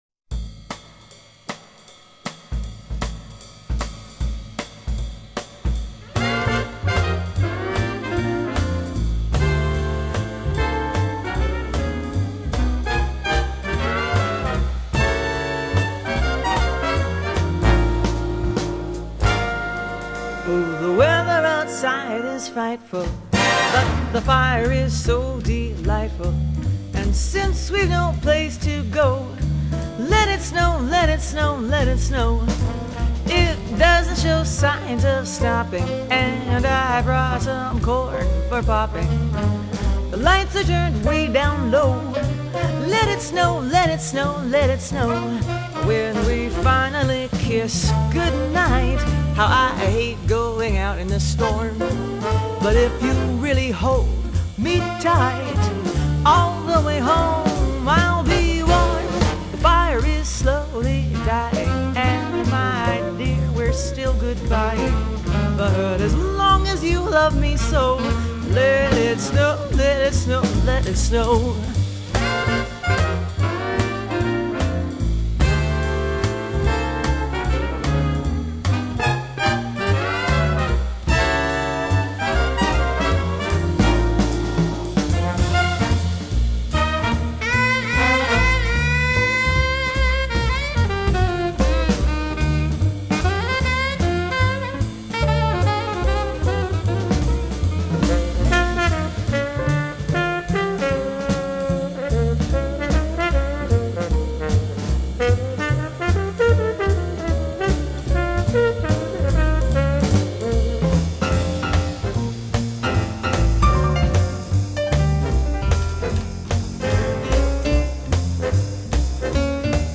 音乐类型： Jazz X'mas爵士乐　　　 　.